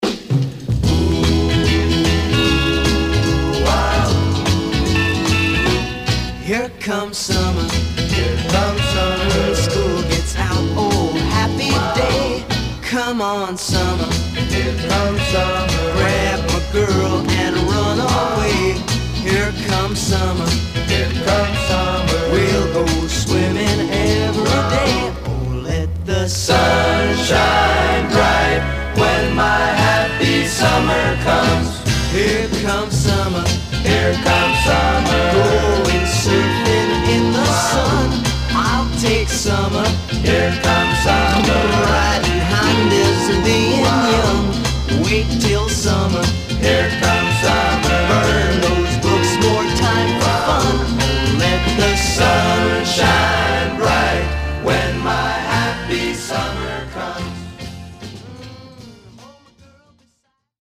Teen
Stereo/mono Mono